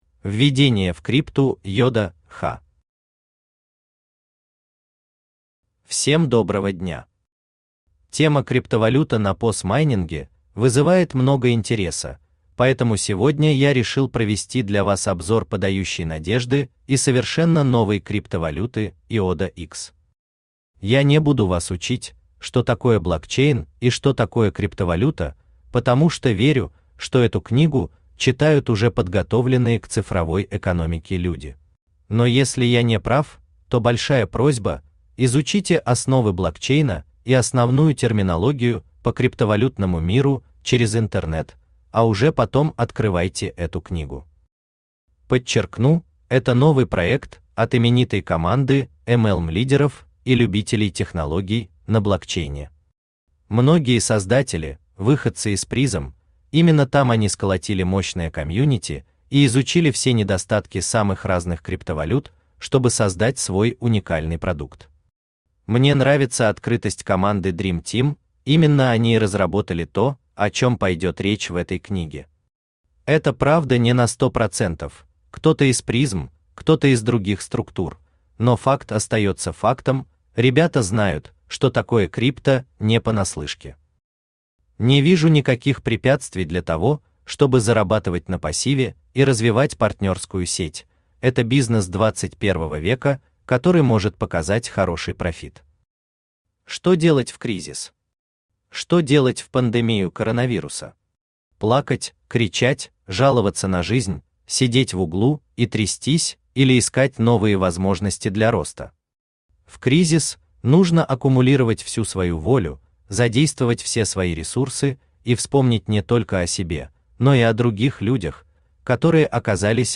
Aудиокнига Криптовалюта Yoda X Автор Руслан Игоревич Захаркин Читает аудиокнигу Авточтец ЛитРес.